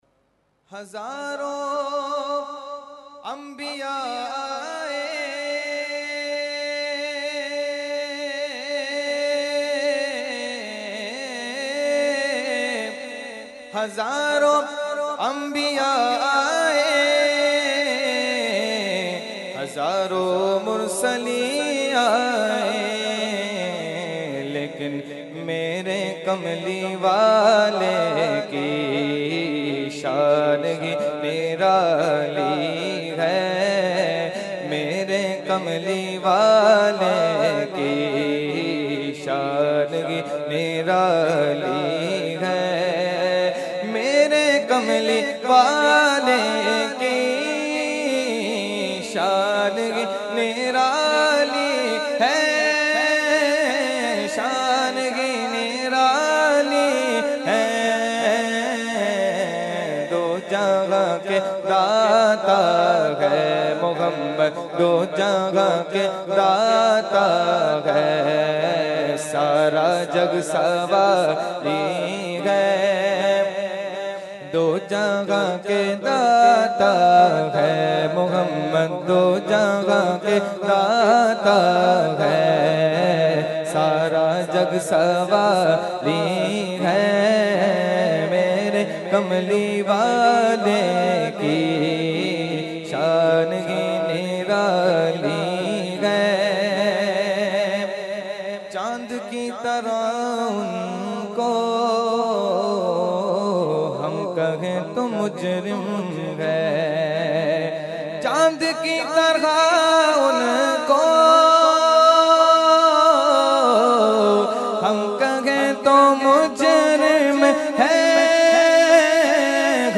Category : Naat | Language : UrduEvent : Urs Ashraful Mashaikh 2019